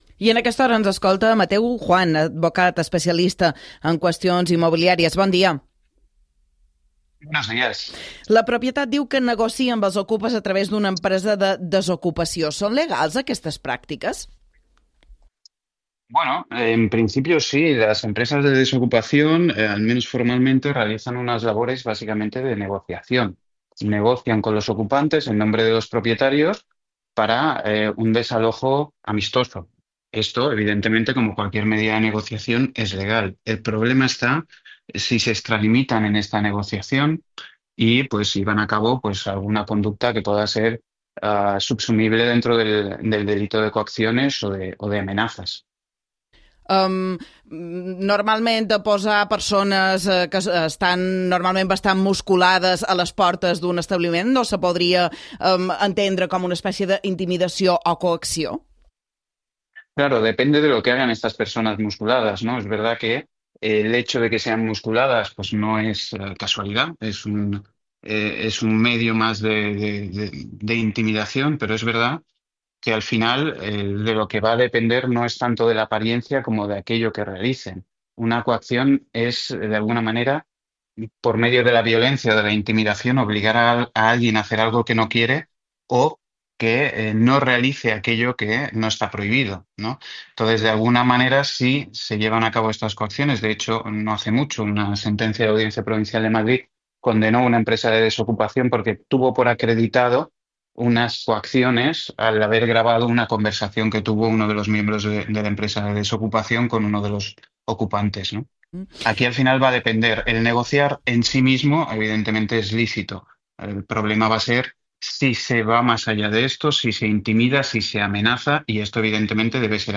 La noticia en el Informatiu Balear de TVE